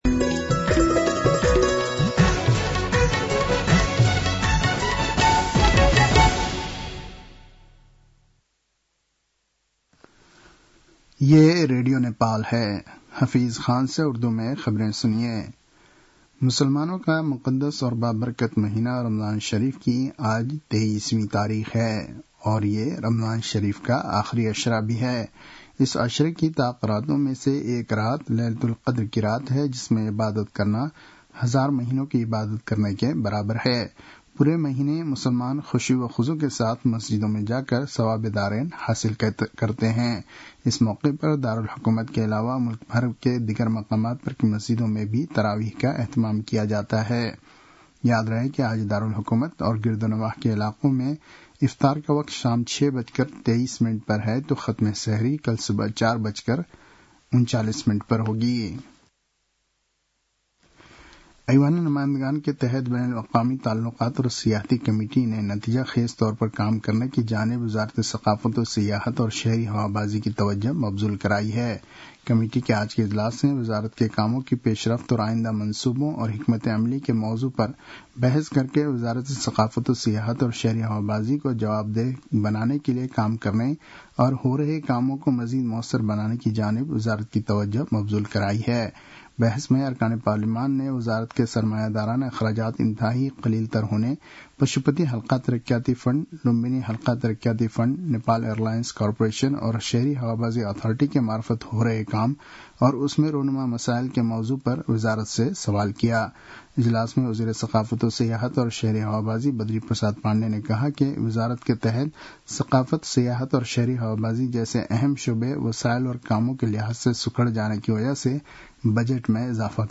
उर्दु भाषामा समाचार : ११ चैत , २०८१